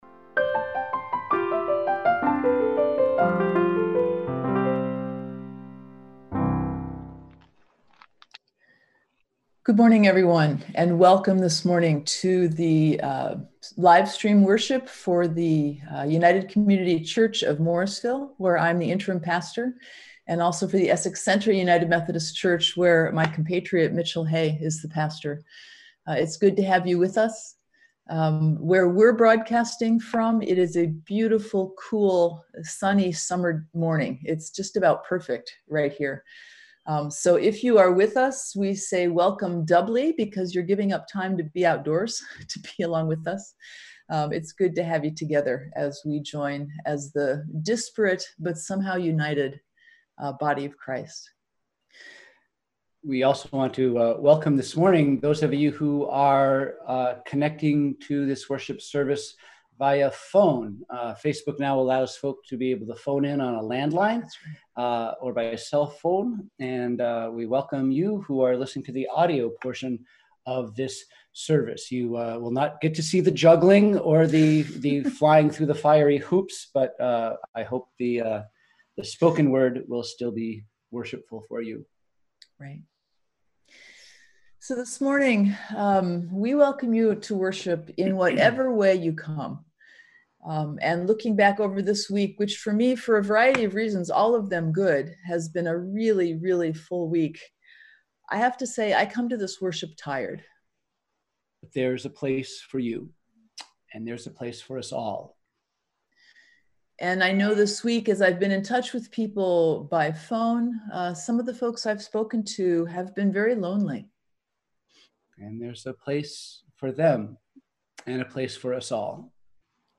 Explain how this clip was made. We held virtual worship on Sunday, June 28, 2020.